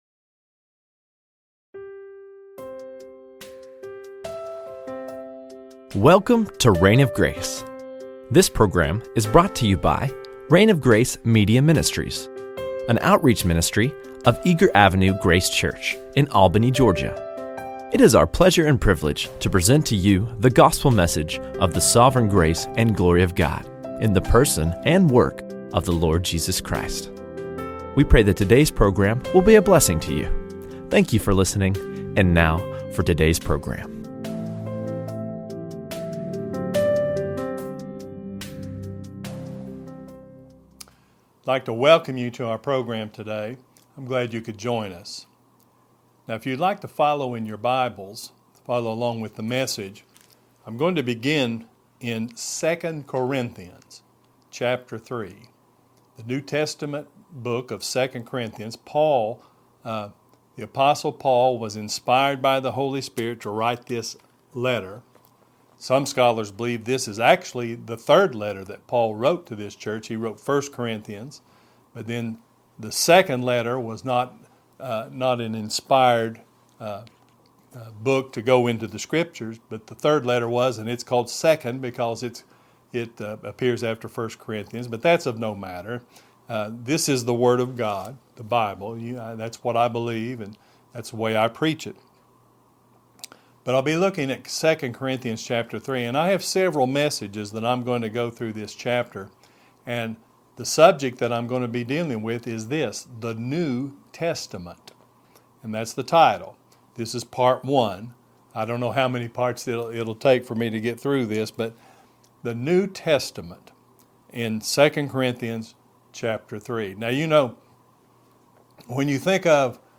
The New Testament - 1 | SermonAudio Broadcaster is Live View the Live Stream Share this sermon Disabled by adblocker Copy URL Copied!